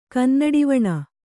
♪ kannaḍivaṇa